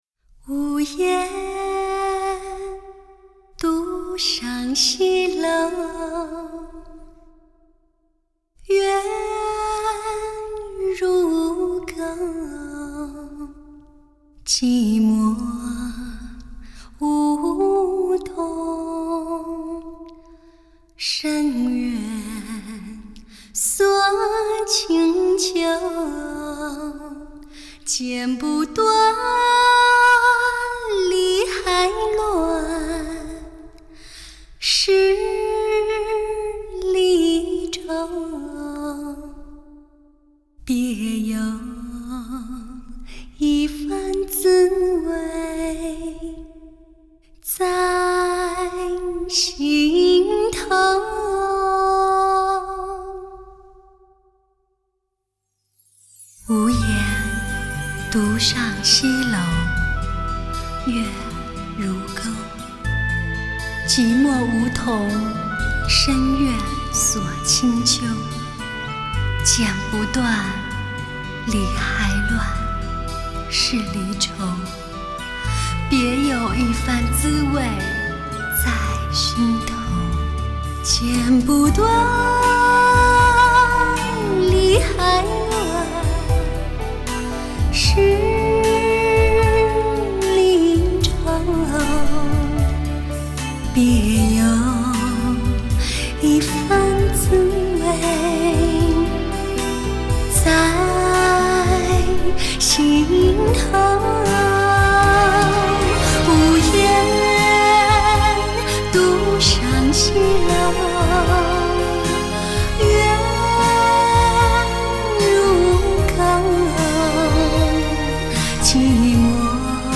绝色女声
聆听人声的极致完美表现，清新脱俗的声音，
最温婉动人的女人心声，如在你耳边娓娓诉来。